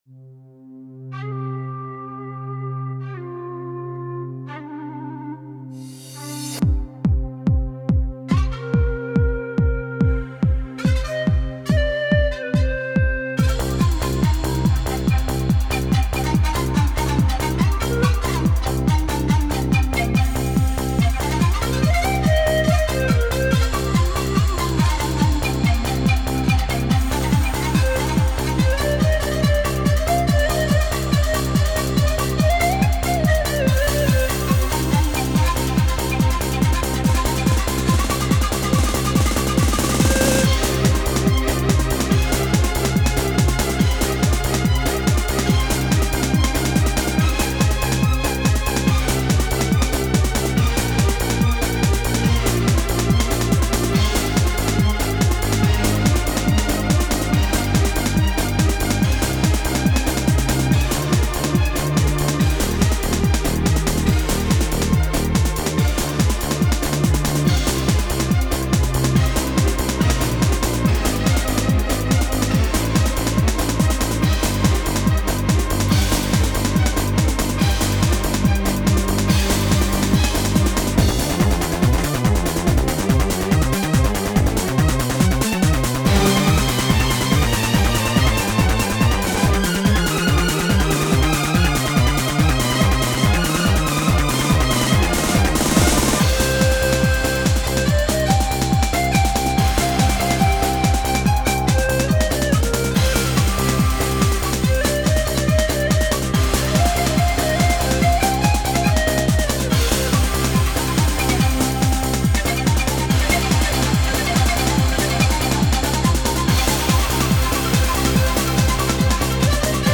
Триголосні інвенції : для фортепіано / И.С. Бах.